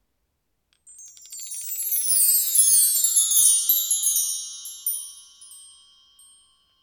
recorded studio chimes 2.wav
Recorded in a professional studio with a Tascam DR 40 linear PCM recorder.
.WAV .MP3 .OGG 0:00 / 0:07 Type Wav Duration 0:07 Size 2,3 MB Samplerate 44100 Hz Bitdepth 2822 kbps Channels Stereo Recorded in a professional studio with a Tascam DR 40 linear PCM recorder.
recorded_studio_chimes_2_fxr.ogg